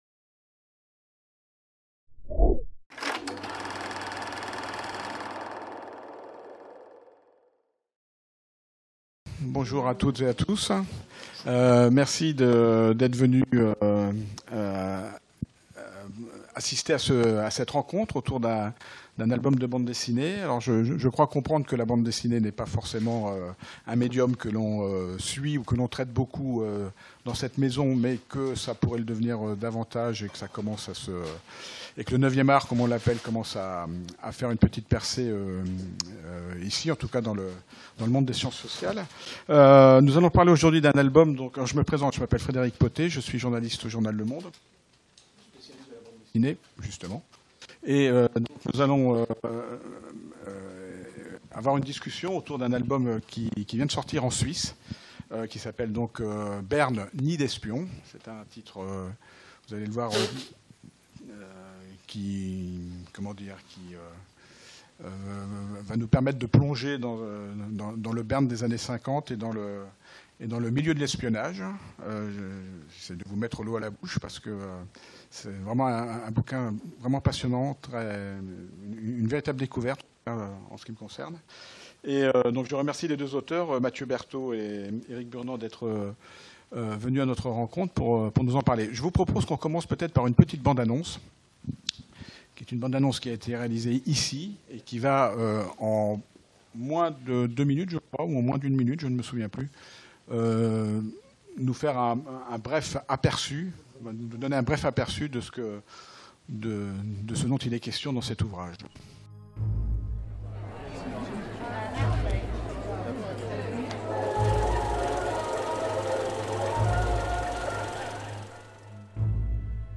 Soirée de présentation de l'ouvrage "Berne nid d'espions" | Canal U